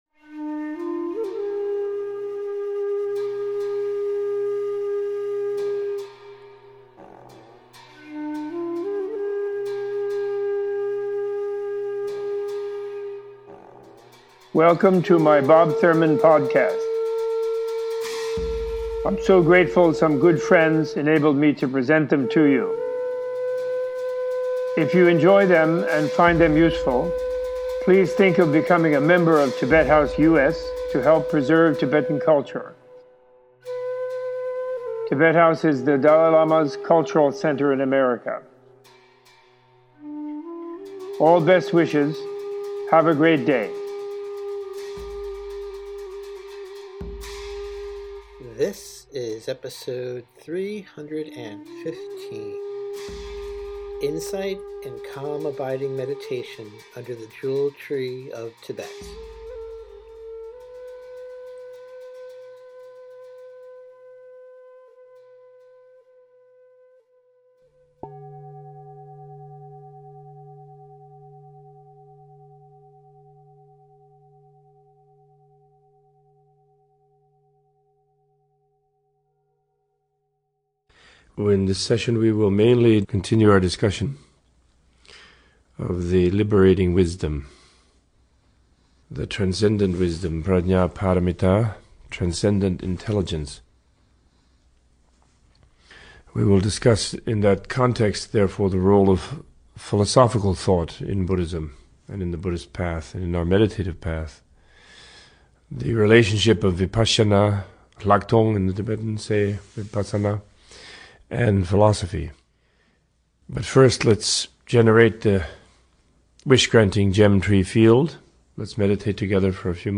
Opening with an extended “Jewel Tree of Tibet” guided meditation, Robert Thurman gives a teaching on using Vipassana and transcendent intelligence to understand the role of philosophical thought in Buddhism.